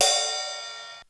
Index of /90_sSampleCDs/300 Drum Machines/Korg DSS-1/Drums02/01
Ride.wav